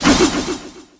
metal_swipe_03.wav